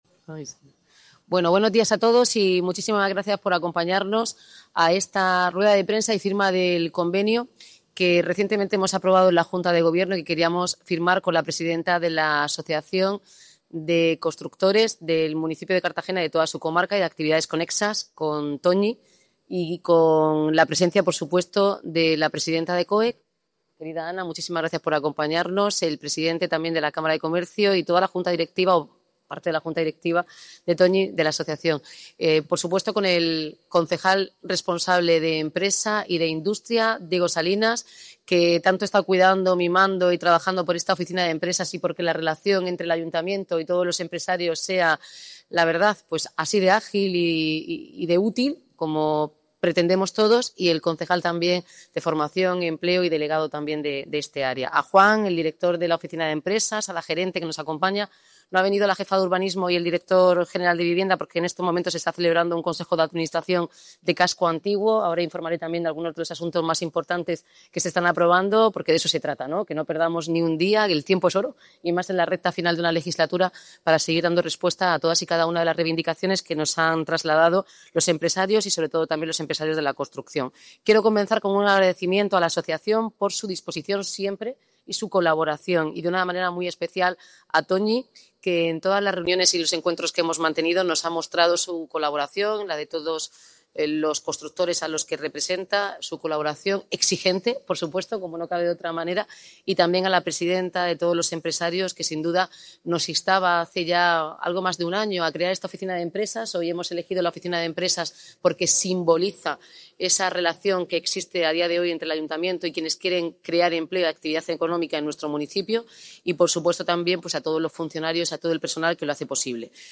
Audio: Declaraciones
El acto, celebrado en la Oficina de Empresa del Consistorio, ha contado con la presencia de la alcaldesa, los concejales de Empleo y Desarrollo Económico, y la junta directiva de la asociación, así como con representantes de COEC y la Cámara de Comercio.